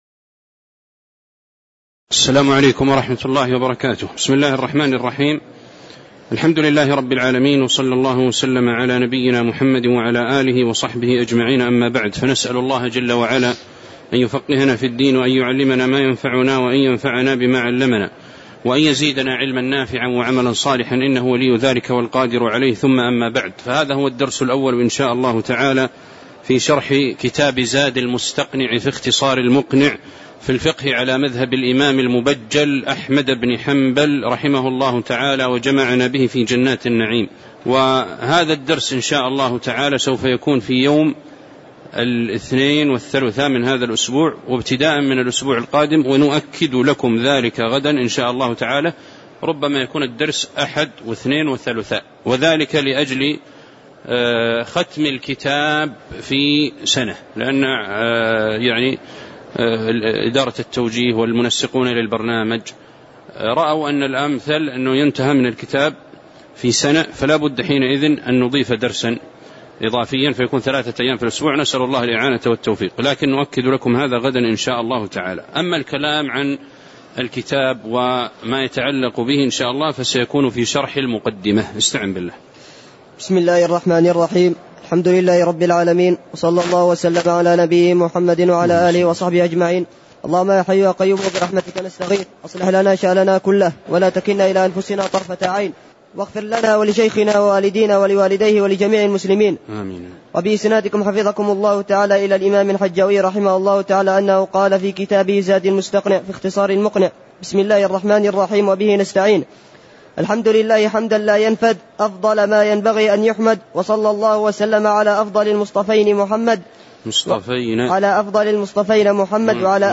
تاريخ النشر ٧ محرم ١٤٤٠ هـ المكان: المسجد النبوي الشيخ